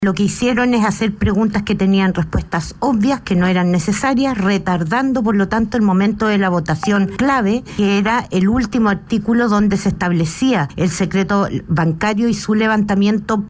La diputada comunista, Alejandra Placencia, también apuntó a tales acciones.